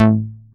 DOWN BASS A4.wav